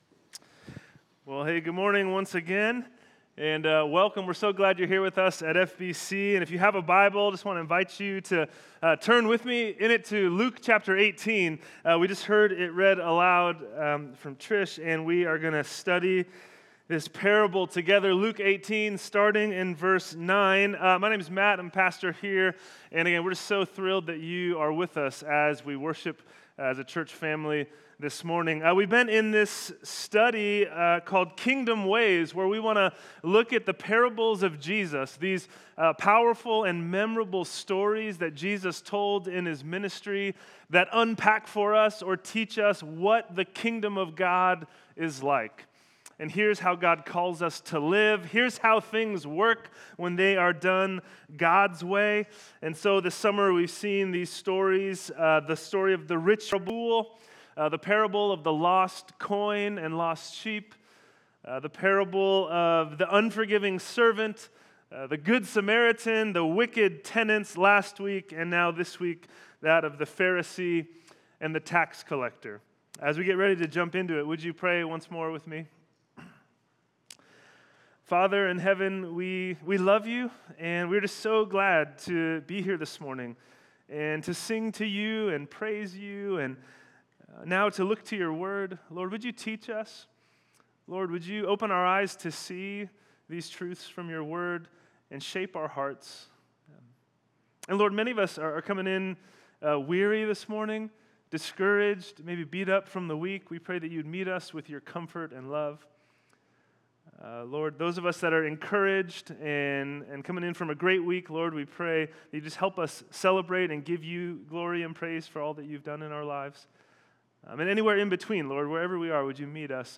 Sermons | First Baptist Church of Benicia